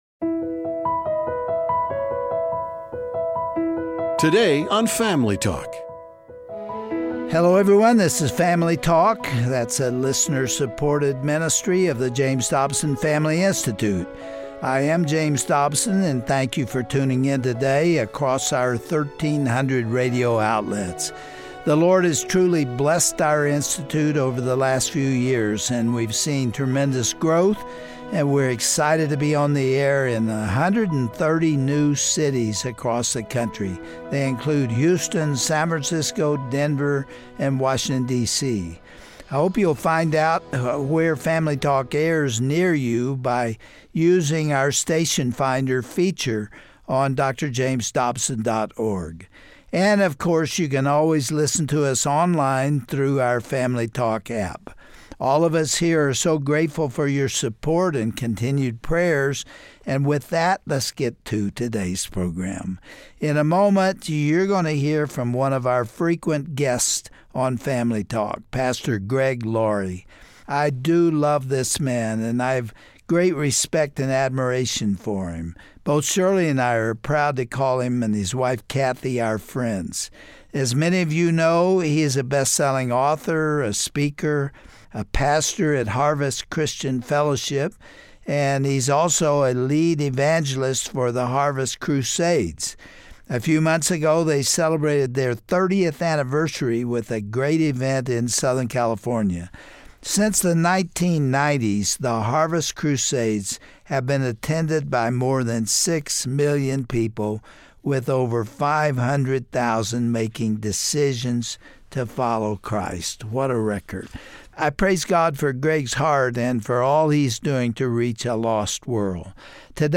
What does the bible say about worry?Pastor Greg Laurie tackles this tough subject through his message at the 2019 Harvest SoCal outreach. He explains the universal problem of anxiousness, but explains Gods remedy for our fears and feelings of hopelessness.